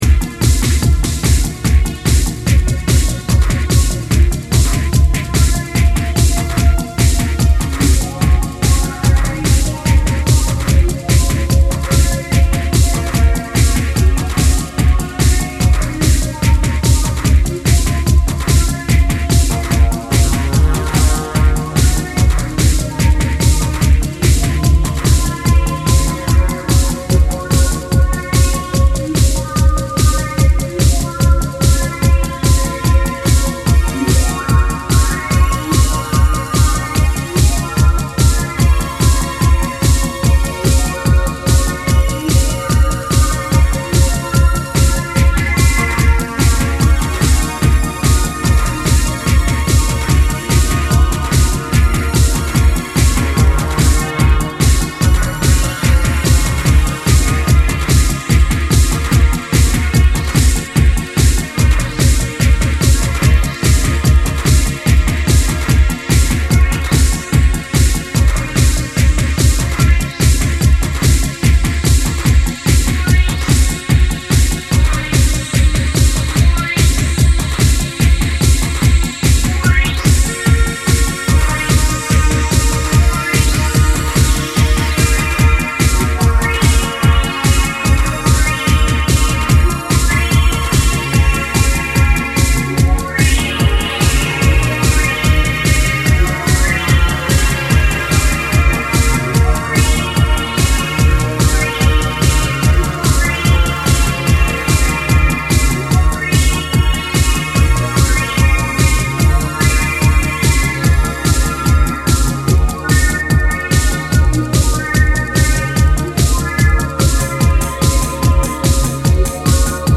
a spellbinding track recorded a long time ago